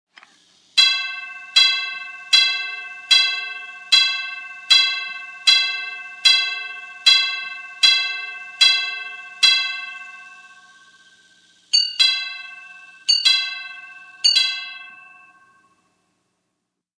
Nautical Clock | Sneak On The Lot
CLOCKS NAUTICAL CLOCK: INT: Nautical clock rings, whirring mechanism.